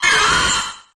cursola_ambient.ogg